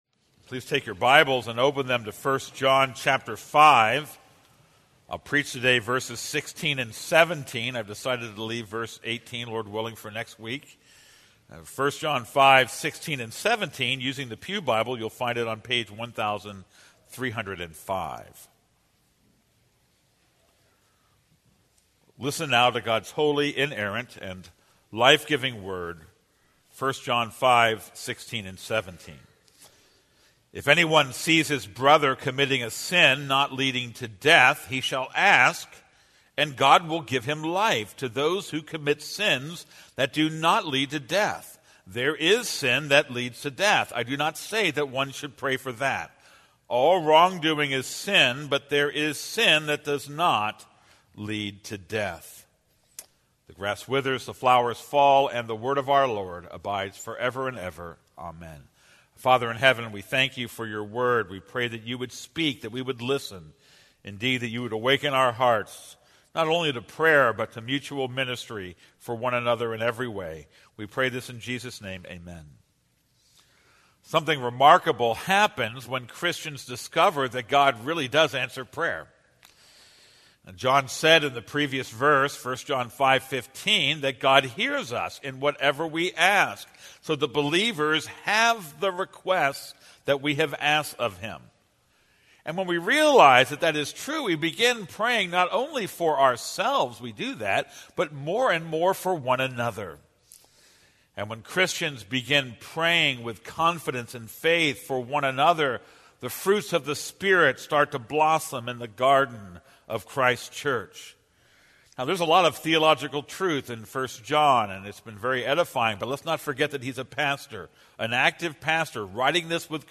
This is a sermon on 1 John 5:16-18.